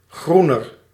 Ääntäminen
Ääntäminen NL: IPA: /ˈxruːnər/ Haettu sana löytyi näillä lähdekielillä: hollanti Käännöksiä ei löytynyt valitulle kohdekielelle.